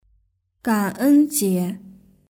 ɡǎn ēn jié